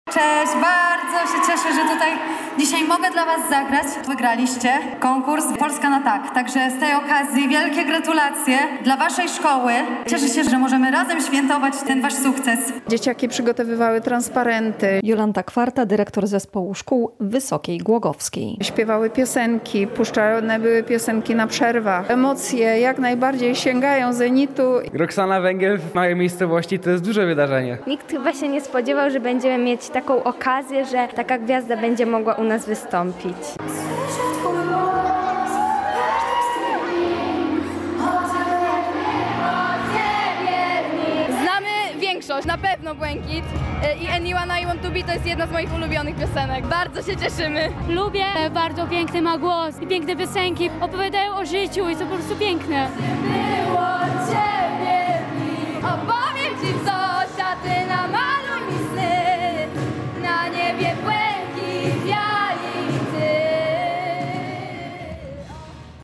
Koncert w Wysokiej Głogowskiej [ZDJĘCIA] • Relacje reporterskie • Polskie Radio Rzeszów